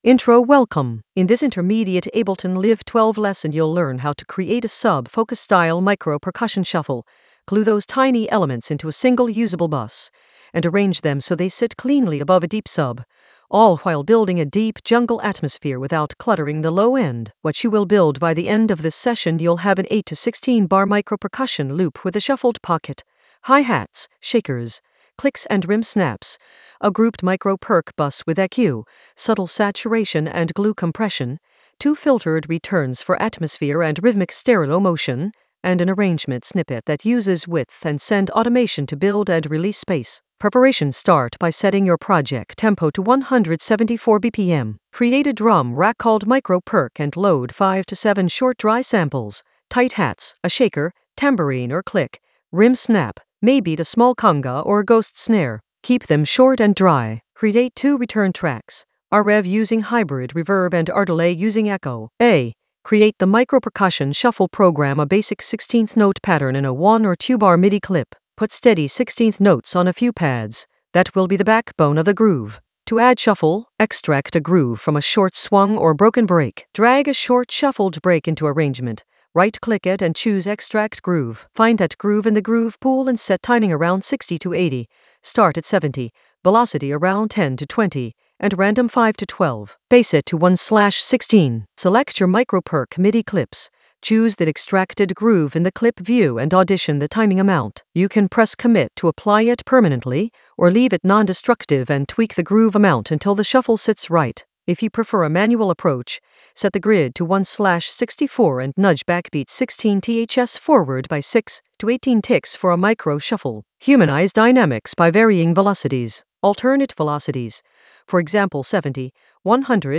An AI-generated intermediate Ableton lesson focused on Sub Focus micro percussion shuffle: glue and arrange in Ableton Live 12 for deep jungle atmosphere in the Mixing area of drum and bass production.
Narrated lesson audio
The voice track includes the tutorial plus extra teacher commentary.